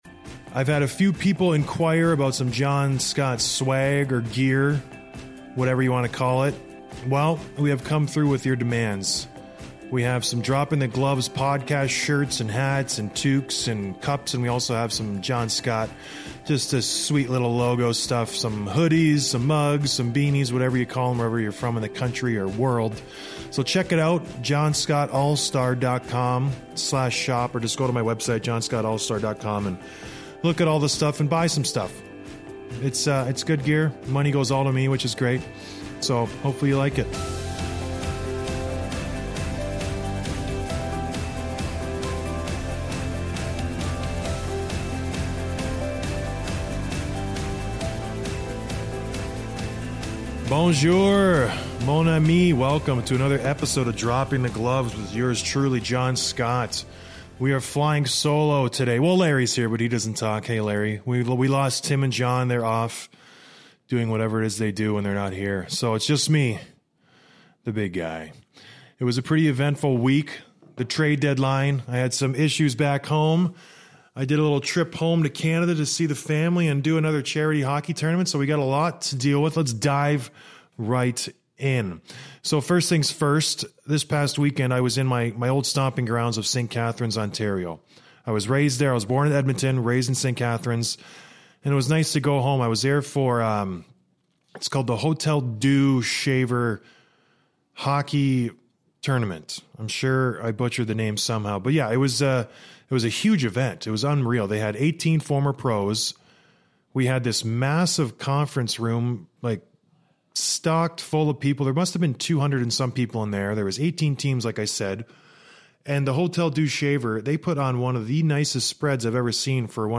I'm riding solo this week but and I have a lot of things to talk about.